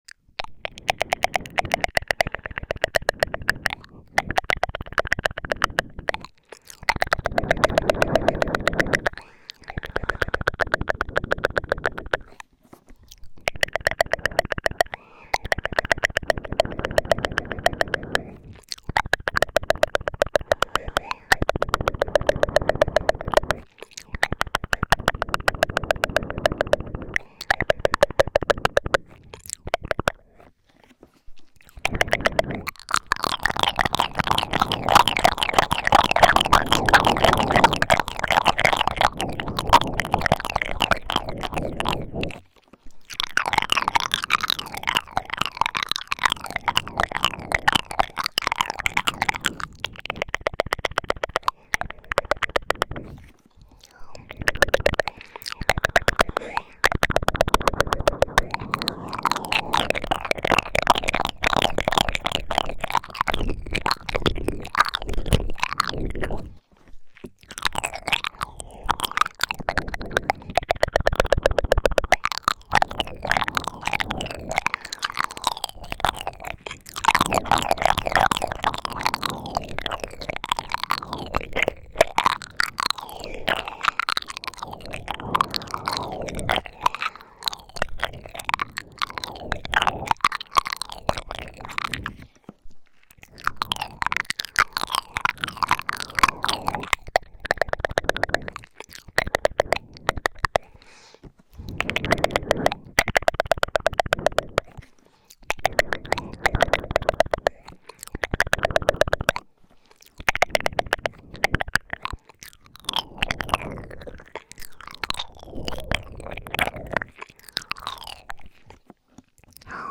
ASMR在线